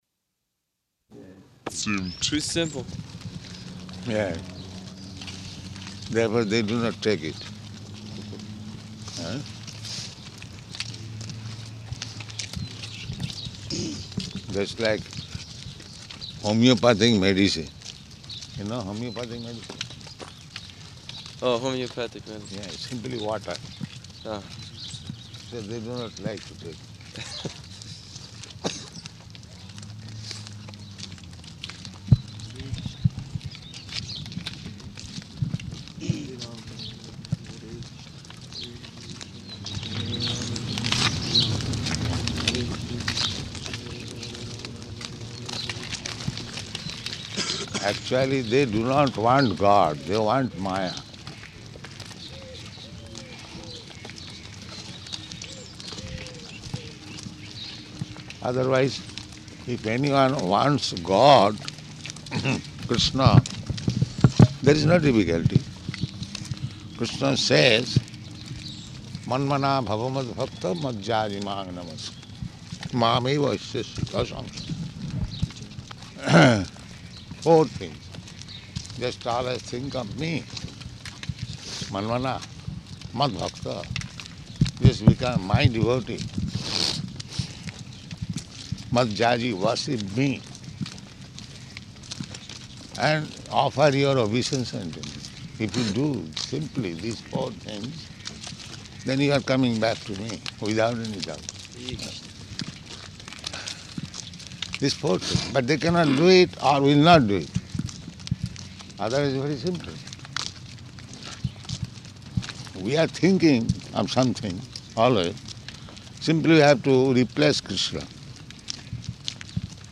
-- Type: Walk Dated: June 9th 1974 Location: Paris Audio file